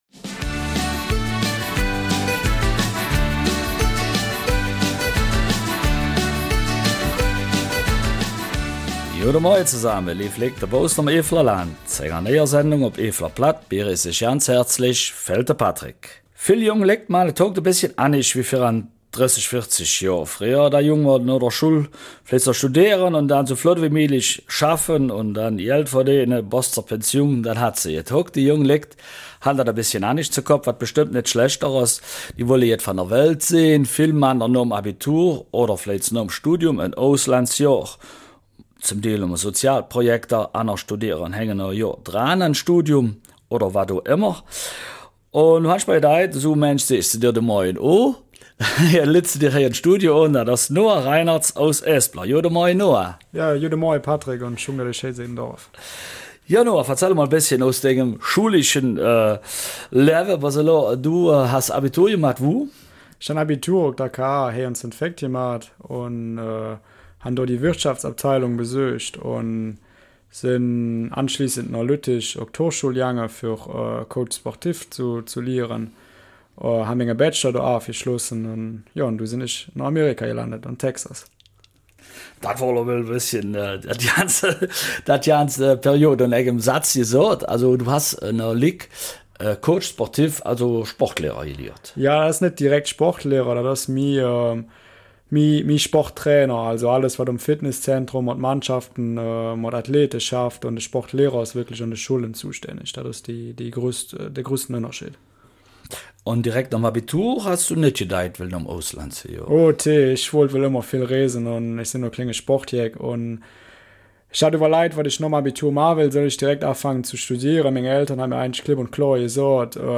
Eifeler Mundart: Studieren im Ausland